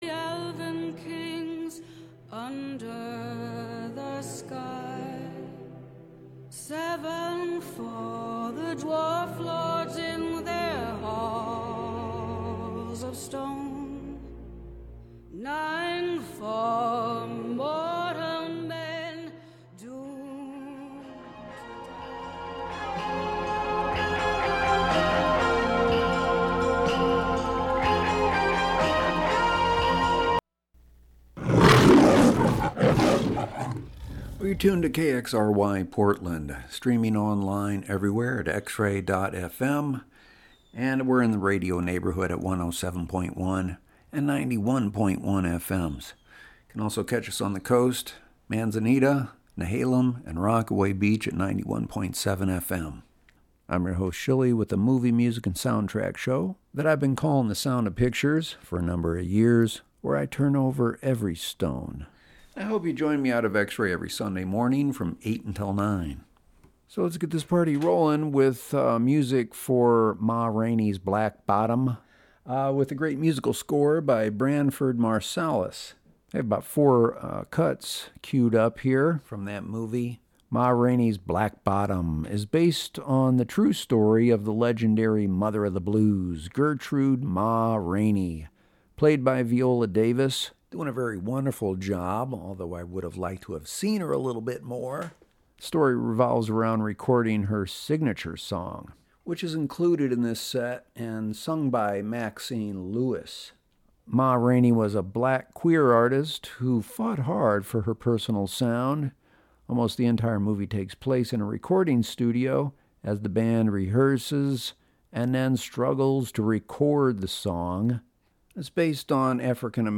movie music show